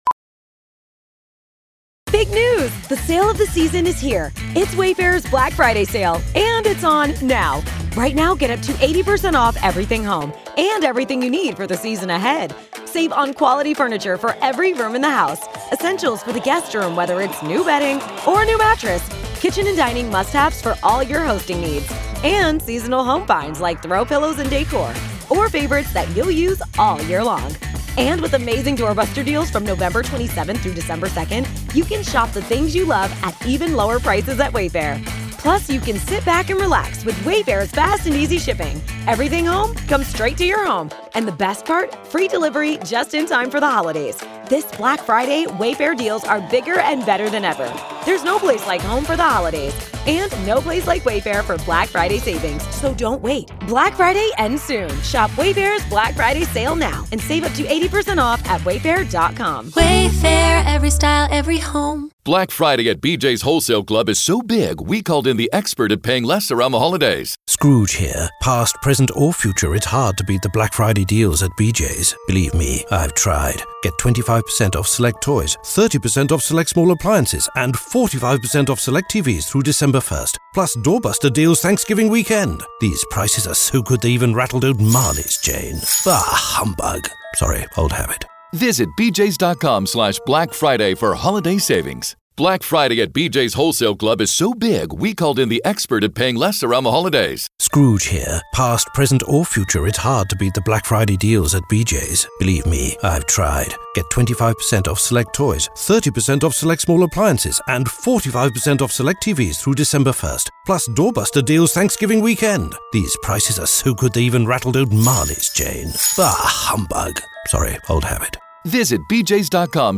Raw, unedited courtroom coverage
Each recording features the full day’s testimony, witness questioning, objections, rulings, and all live developments direct from the courtroom — presented exactly as they happened, without edits or commentary.